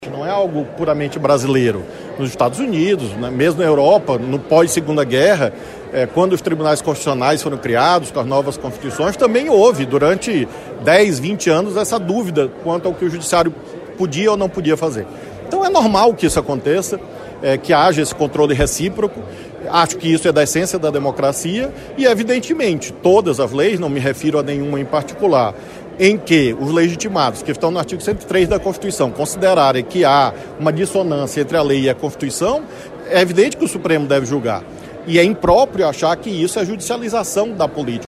No evento, o ministro destacou que a democracia está sendo atacada diante do descontentamento da população com os problemas sociais e ambientais. No tema de sua palestra, ele disse que existem questões nas quais a Justiça precisa intervir, devido à ausência de uma legislação vigente.